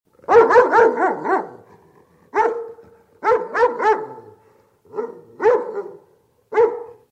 Собака Лает